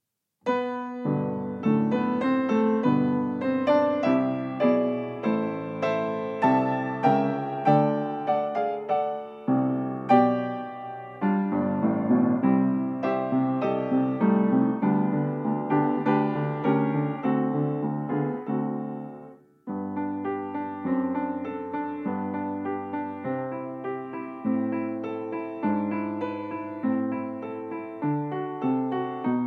akompaniamentu pianina
Nagrane z metronomem, z uwzględnieniem zwolnień.
I część: 100 bmp
Nagranie dokonane na pianinie Yamaha P2, strój 440Hz
piano